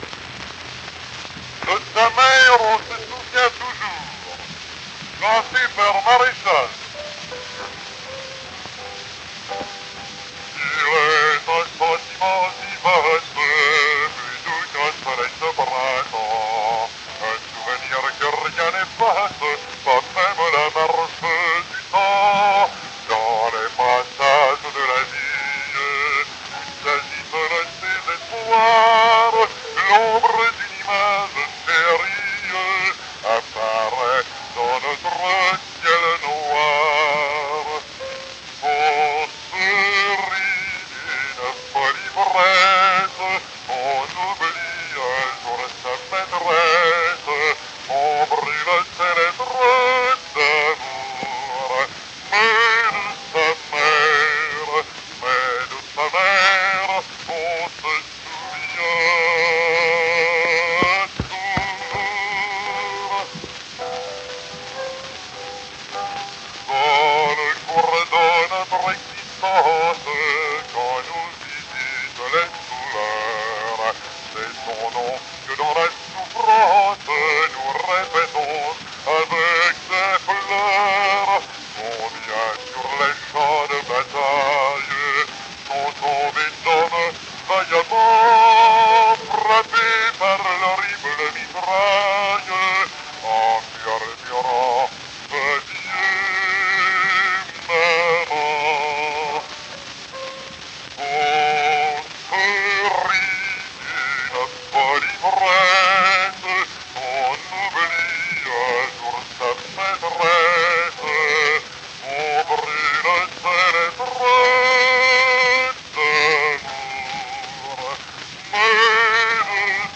Tenor mit Klavierbegleitung.